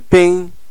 Ääntäminen
IPA: [pæ̃] France (Avignon)